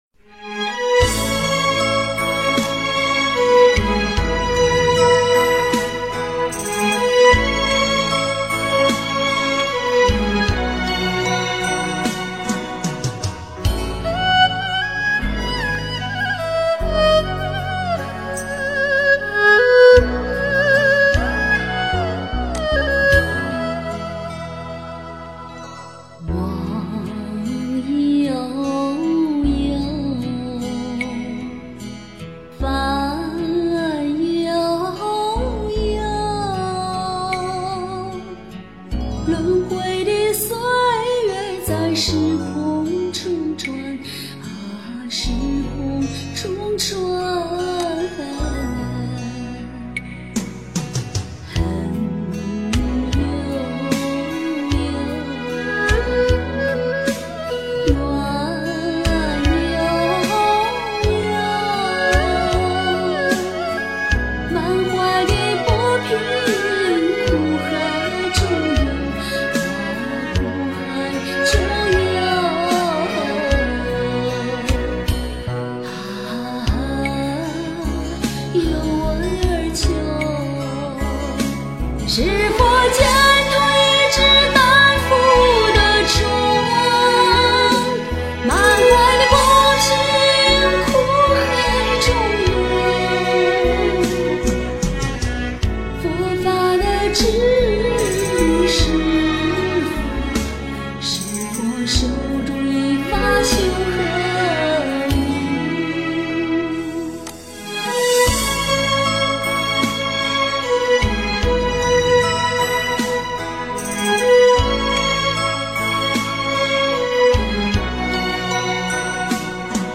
阴阳分 诵经 阴阳分--佛教音乐 点我： 标签: 佛音 诵经 佛教音乐 返回列表 上一篇： 修行的果实 下一篇： 勇气 相关文章 解深密经-5（念诵） 解深密经-5（念诵）--未知...